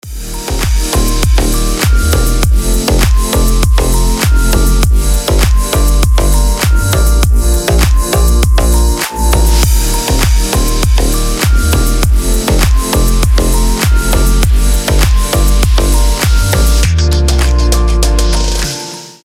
deep house
без слов
красивая мелодия
звонкие
Достаточно громкая мелодия на будильник или звонок